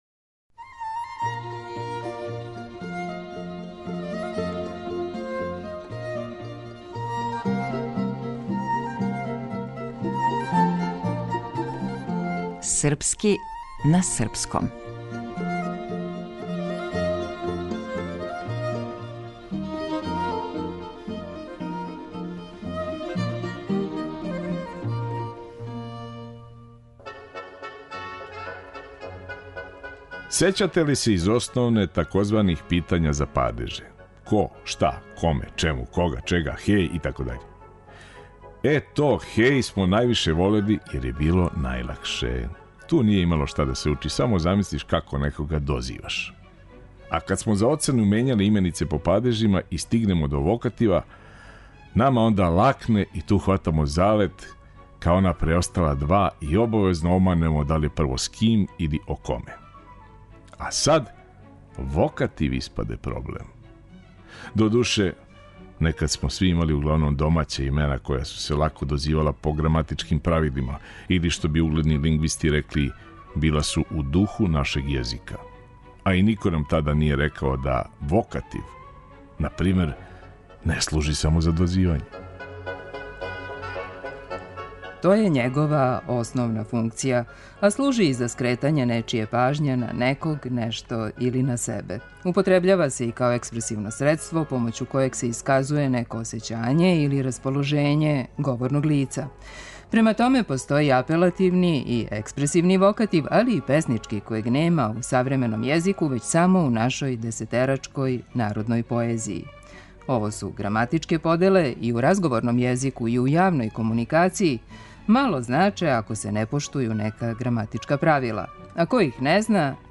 Глумац - Никола Којо